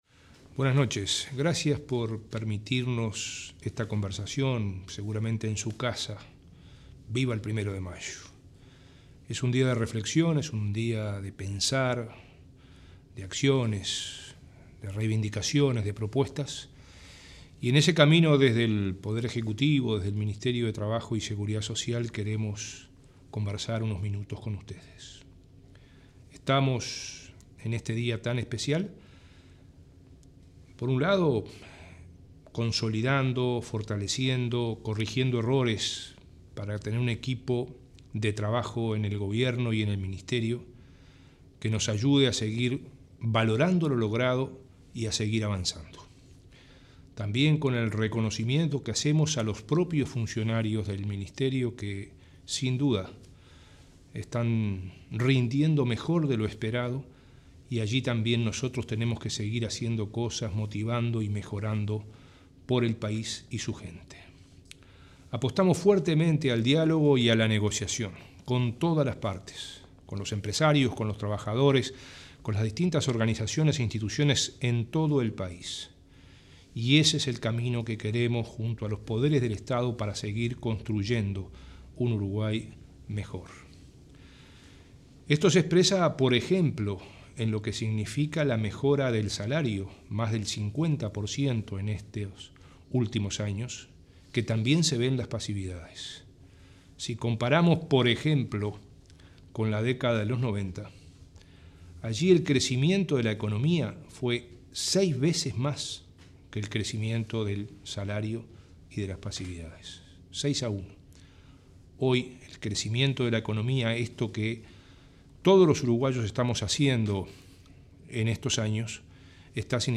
En el Día de los Trabajadores, este 1.º de mayo, el ministro de Trabajo y Seguridad Social, Ernesto Murro, en su mensaje por radio y televisión, destacó la apuesta al diálogo y a la negociación como el camino a seguir. Recordó que la OIT reconoció a Uruguay como primero en América Latina, por su actividad en seguridad social integral, y cuarto en el mundo y primero en la región, por los convenios internacionales ratificados.